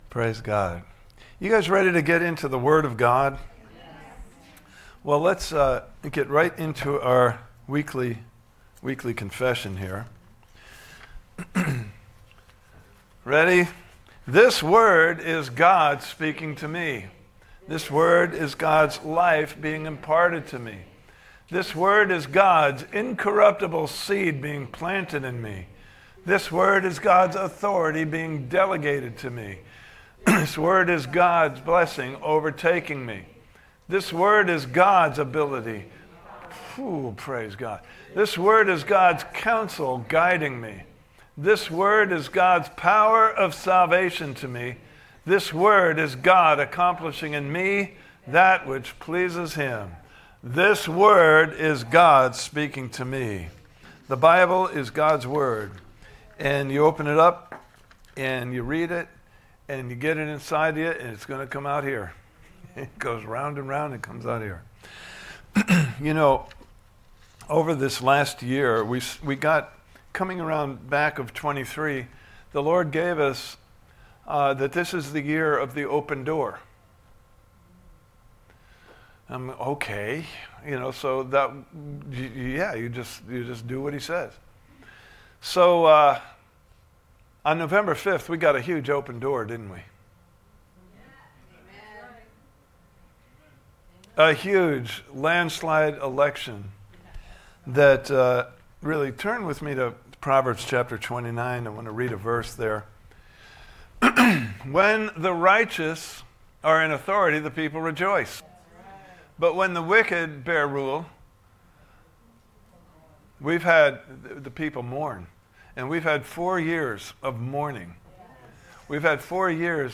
The Art of Prayer Service Type: Sunday Morning Service « Part 3